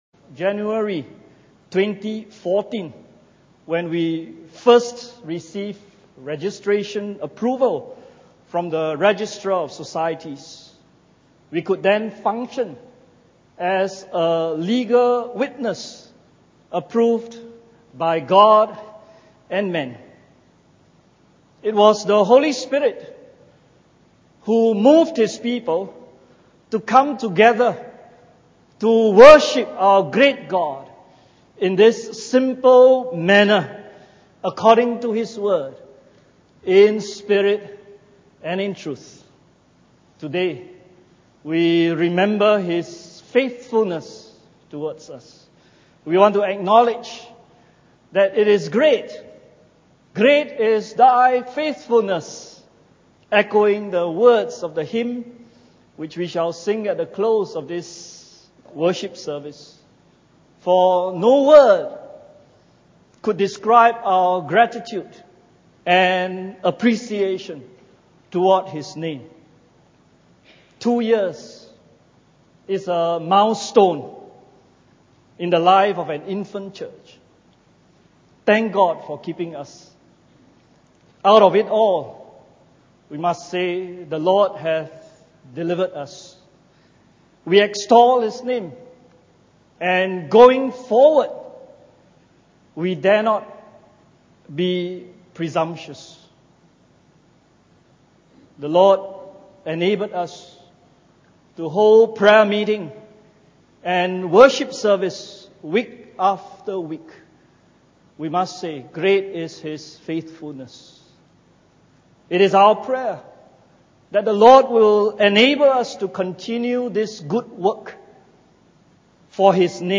2nd Anniversary Thanksgiving Service – Great Is Thy Faithfulness